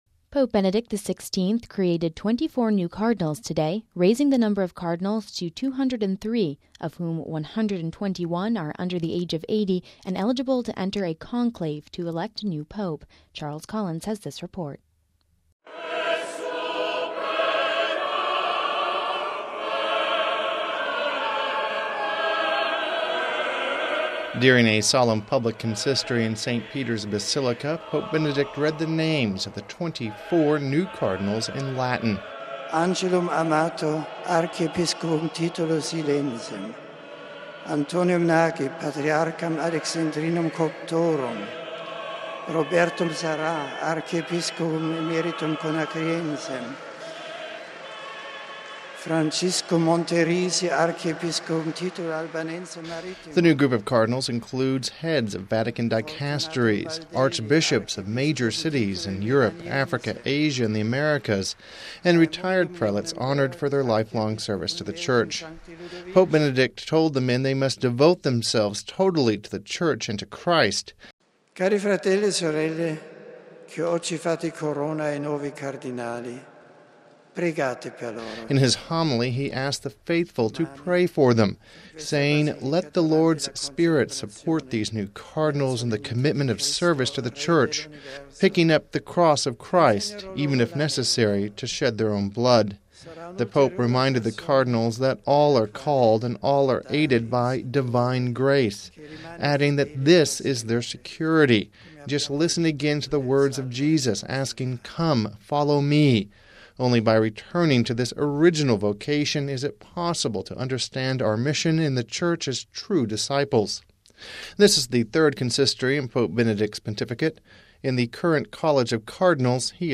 Pope Benedict XVI created 24 new cardinals on Saturday, raising the number of Cardinals to 203, of whom 121 are under 80 and eligible to enter a conclave to elect a new pope. During a solemn public consistory in St. Peter’s Basilica, Pope Benedict read the names of the 24 Cardinals in Latin.